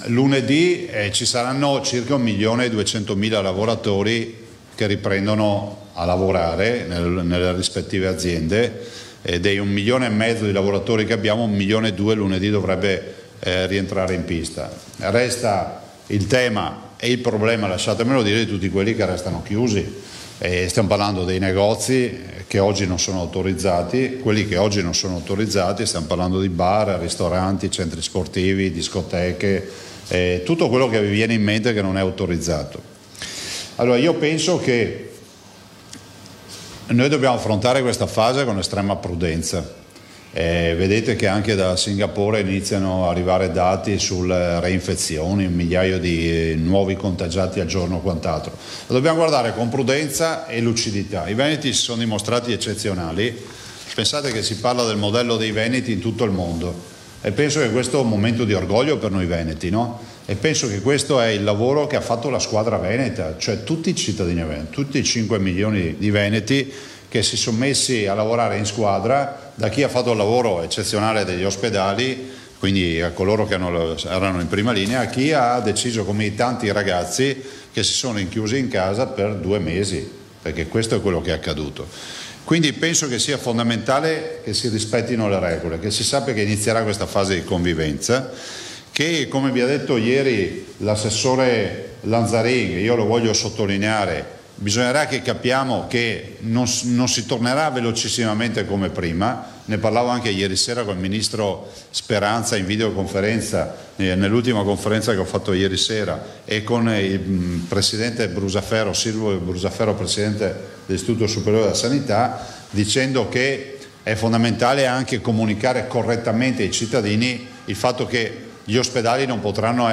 LE ORDINANZE, COSA SUCCEDE LUNEDI, SECONDE CASE…DALLA CONFERENZA STAMPA DI ZAIA